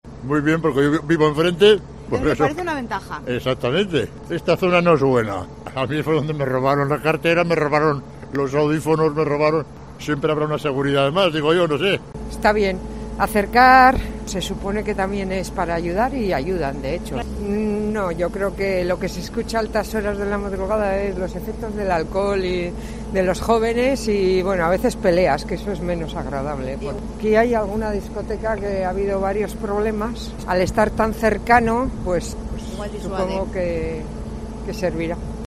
COPE Euskadi pregunta a vecinos del centro de Vitoria sobre la nueva comisaría de la Polica Local